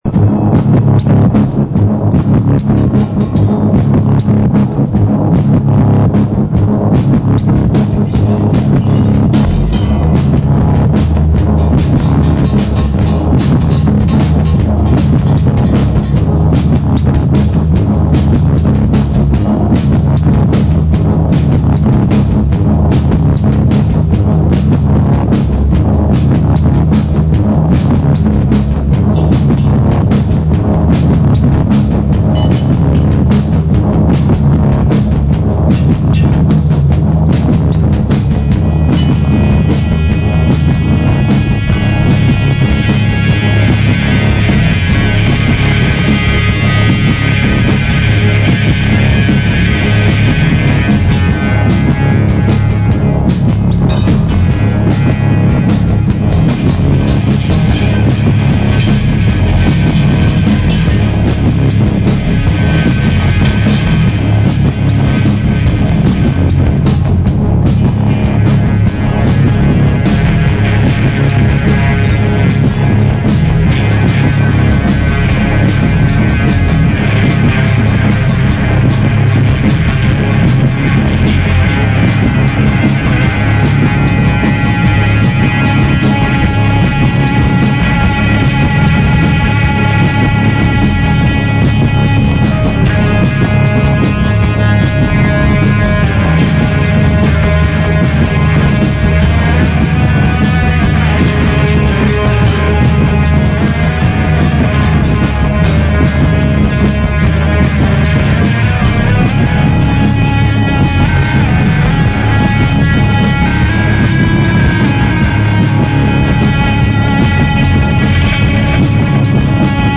live in Helsinki, 2000